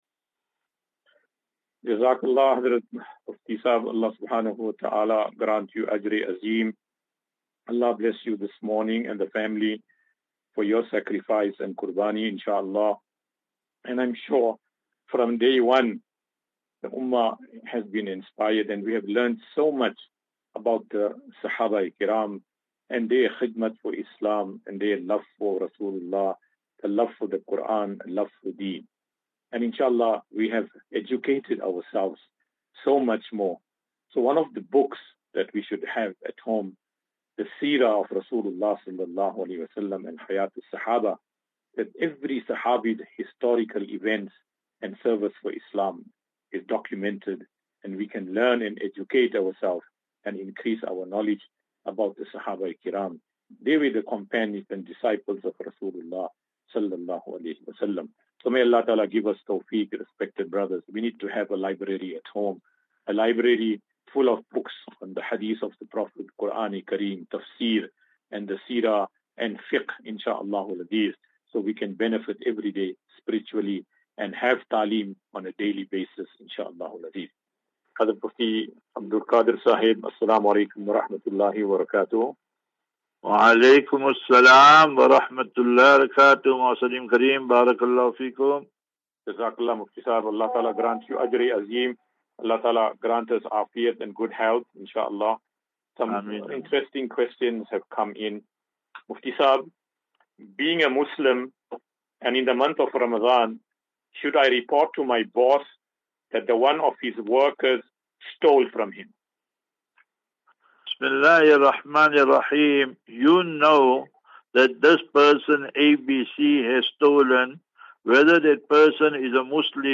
View Promo Continue Install As Safinatu Ilal Jannah Naseeha and Q and A 24 Mar 24 March 2024.